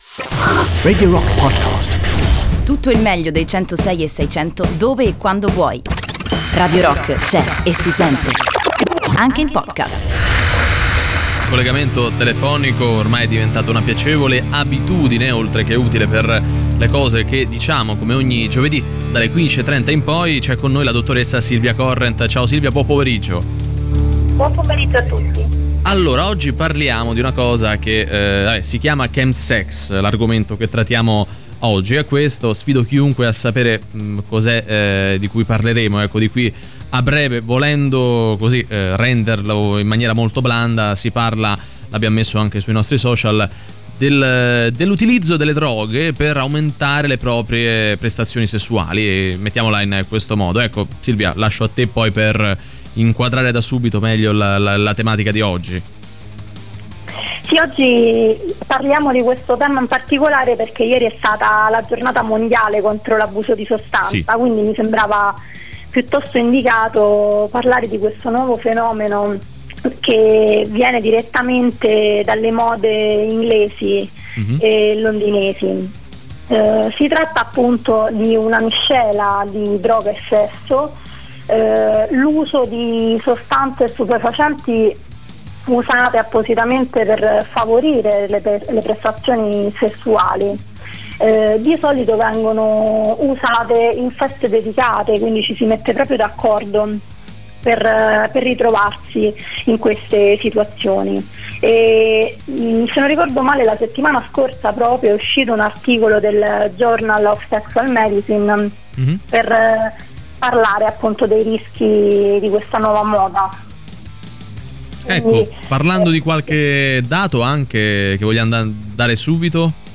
In collegamento telefonico